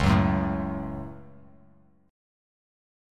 Dbsus4#5 chord